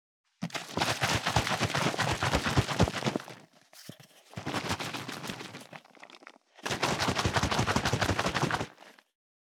339ペットボトル,ワインボトルを振る,水の音,ジュースを振る,シャカシャカ,カシャカシャ,チャプチャプ,ポチャポチャ,シャバシャバ,チャプン,ドボドボ,グビグビ,パシャパシャ,ザバザバ,ゴボゴボ,ジャブジャブ,シュワシュワ,プシュッ,シュッ,ドクドク,ポン,バシャ,ブルブル,ボコボコ,
ペットボトルワイン効果音厨房/台所/レストラン/kitchen